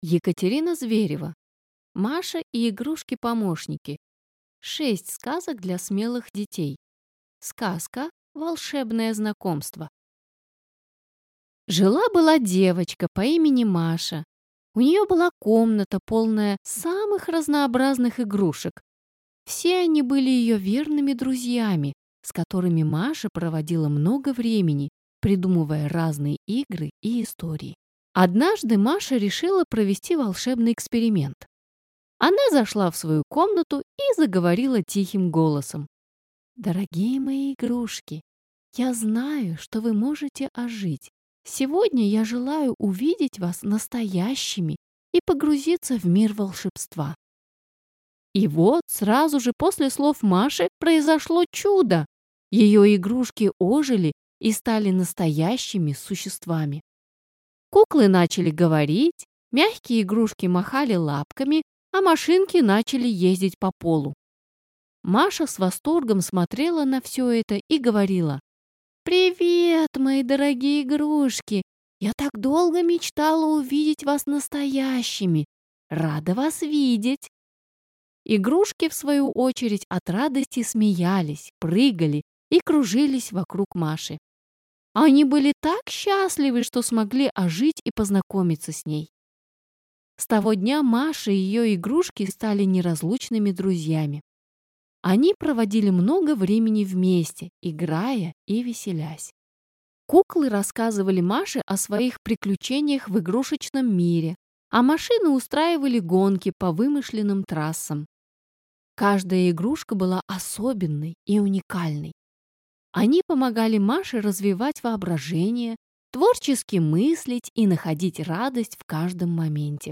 Аудиокнига Маша и игрушки-помощники: 6 сказок для смелых детей | Библиотека аудиокниг
Прослушать и бесплатно скачать фрагмент аудиокниги